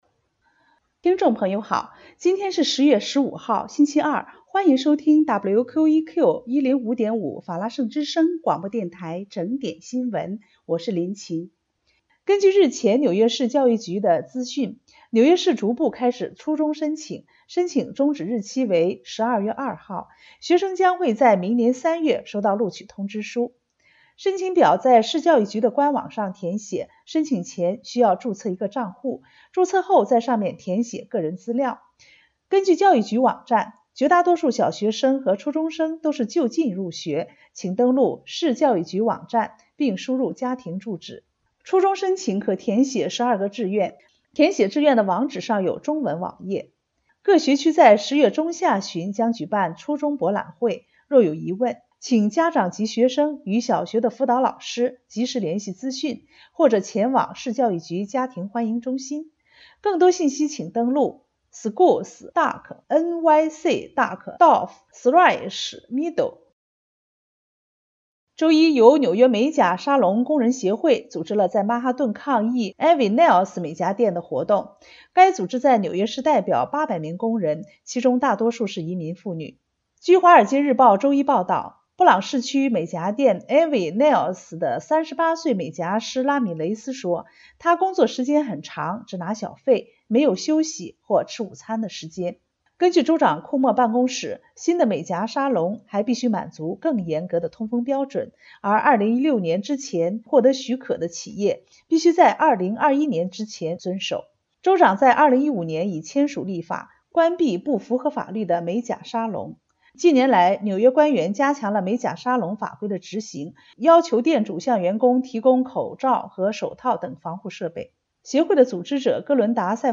2019年4月23号（星期二） 纽约整点新闻
听众朋友您好！今天是4月23号，星期二，欢迎收听WQEQFM105.5法拉盛之声广播电台整点新闻。